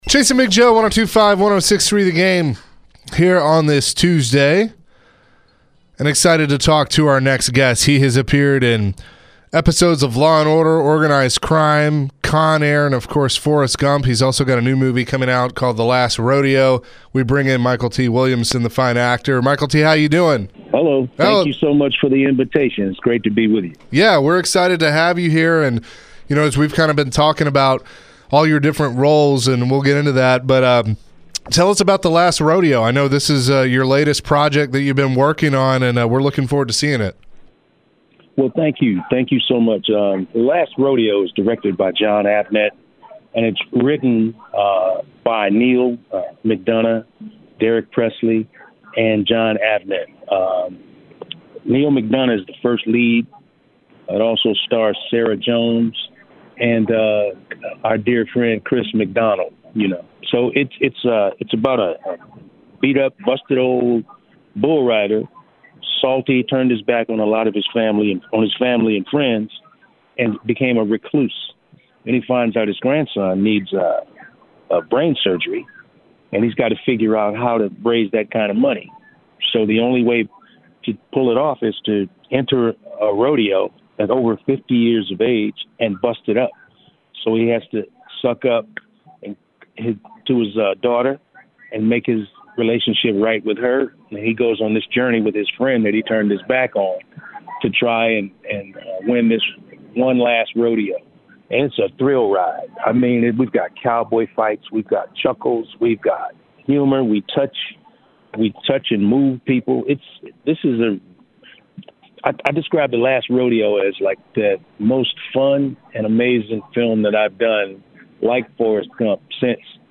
The guys chatted with actor Mykelti Williamson. Mykelti talked about his role in Forrest Gump, along with his new movie on the rise.